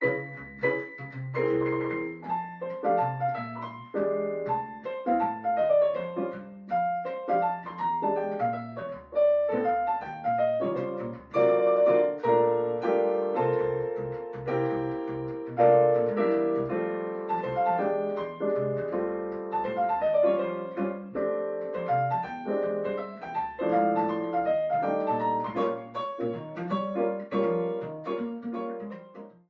A piano arrangement